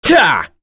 Roi-Vox_Attack8.wav